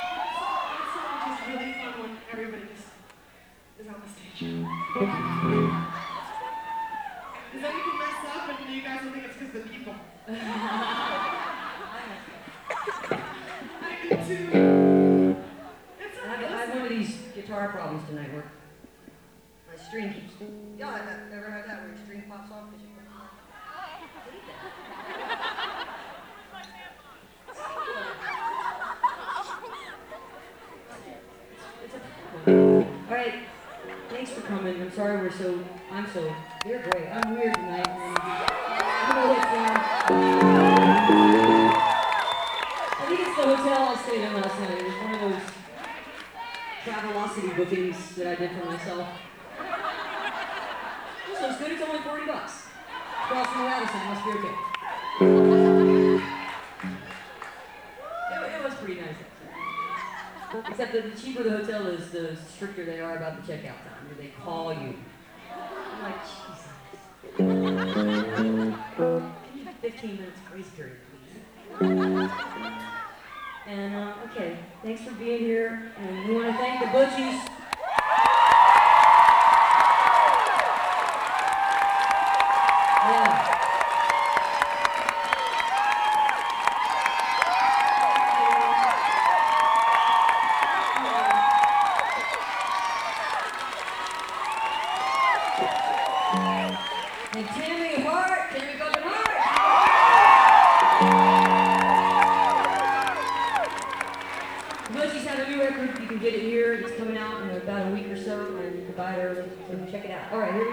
lifeblood: bootlegs: 2001-04-04: 9:30 club - washington, d.c. (amy ray and the butchies)
16. talking with the crowd (1:50)